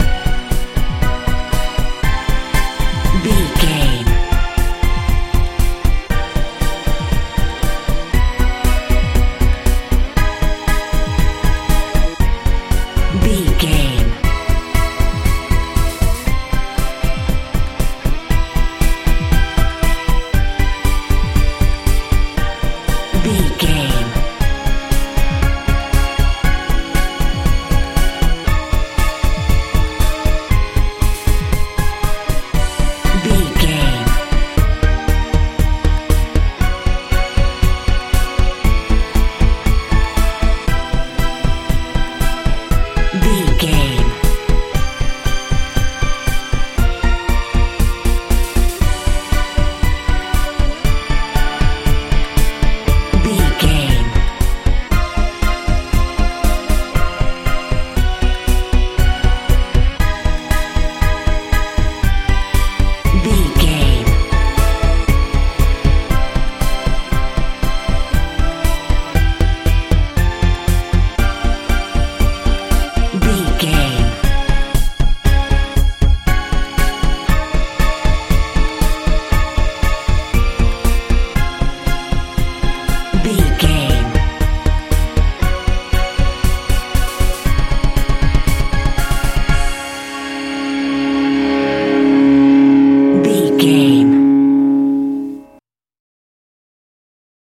modern pop feel
Ionian/Major
mystical
magical
bass guitar
drums
synthesiser
80s
90s
strange